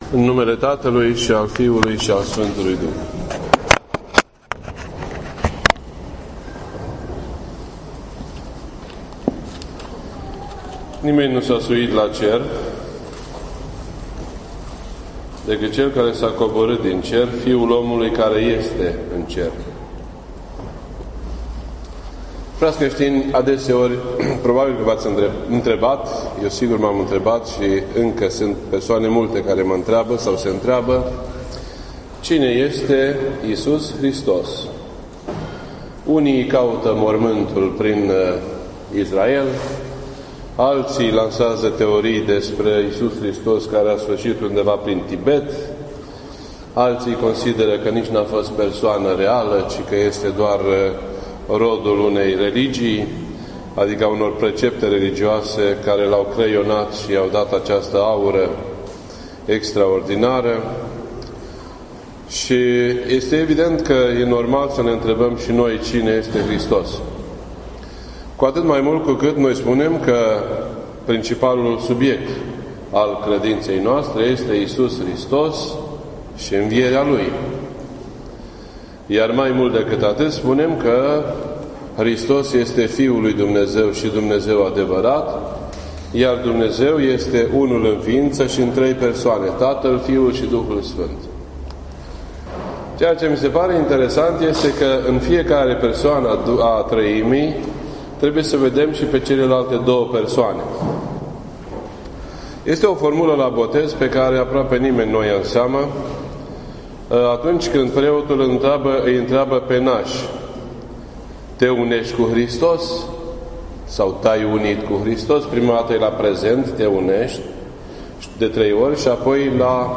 This entry was posted on Sunday, September 11th, 2016 at 10:33 AM and is filed under Predici ortodoxe in format audio.